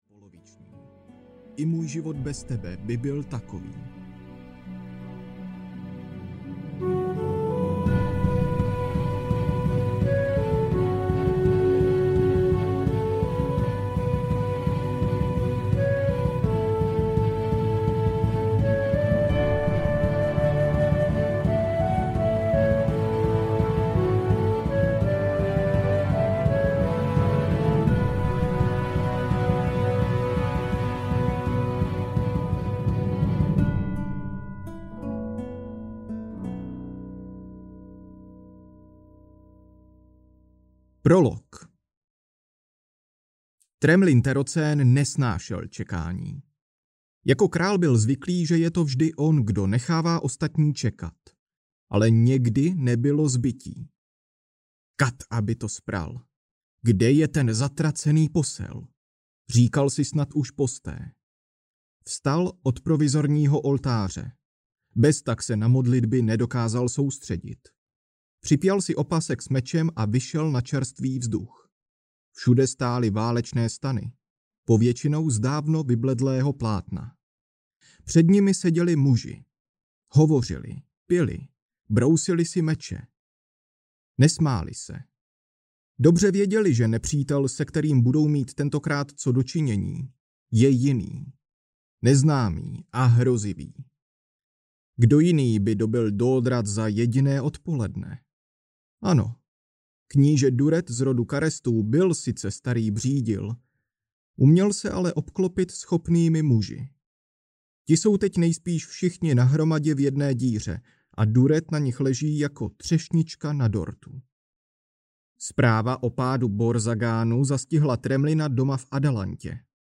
Poloviční král audiokniha
Ukázka z knihy